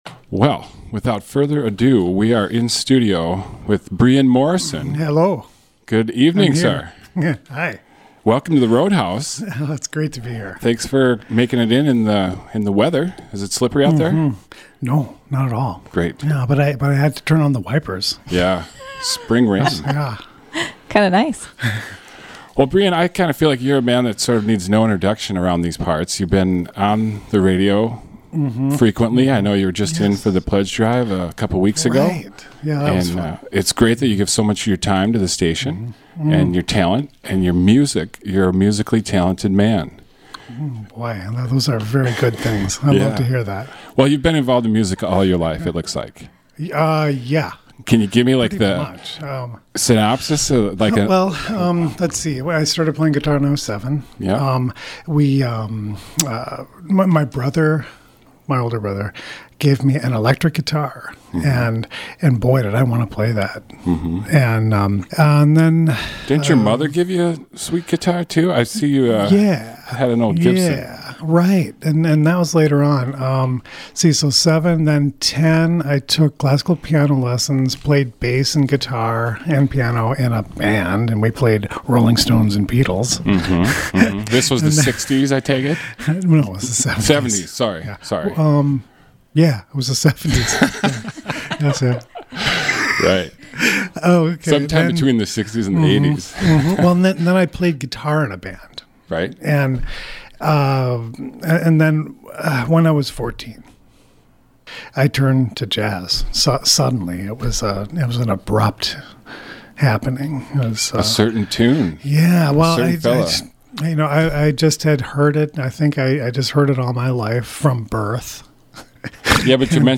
local jazz guitarist and composer
In this interview he explains the project and share three of the 12 songs.